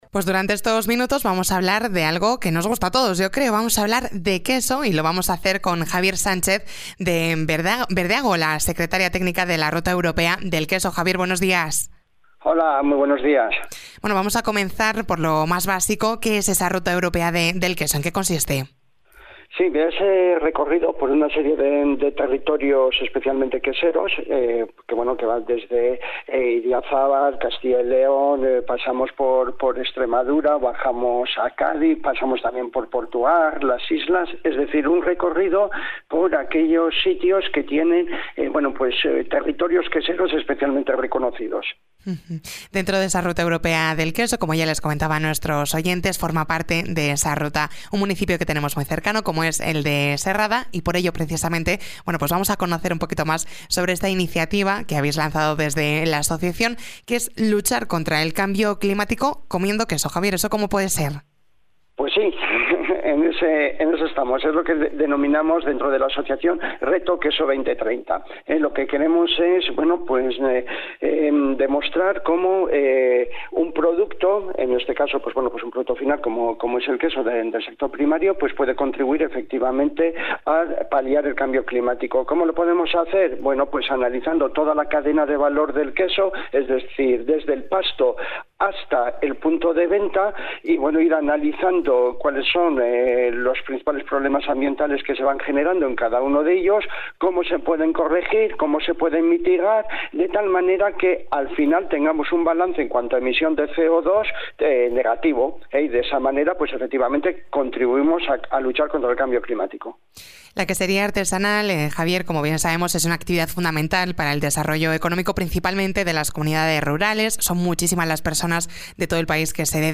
Berdeago asume la secretaría técnica de la Asociación Ruta Europa del Queso con el objetivo de promocionar el turismo sostenible a través del queso. Escucha la entrevista en Onda Cero